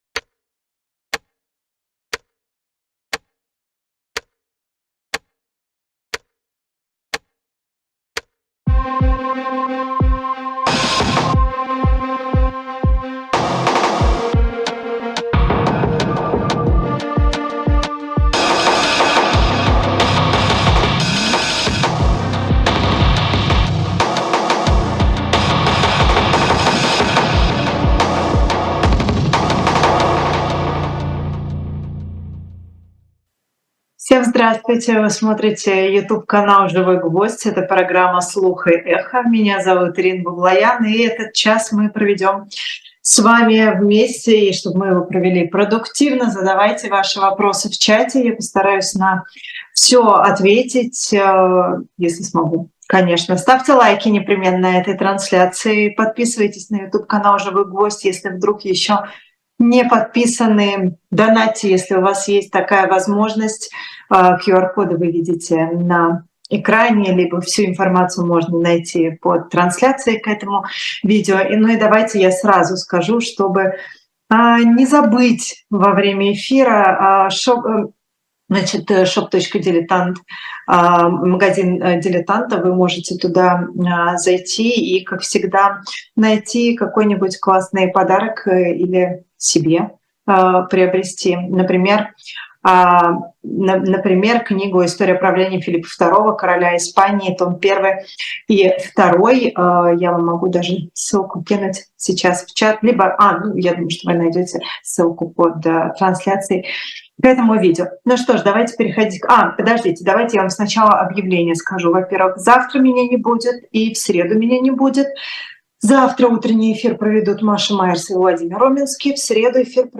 На ваши вопросы в прямом эфире отвечает